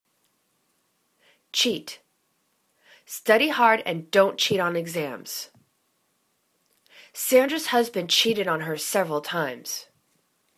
cheat     /che:t/    v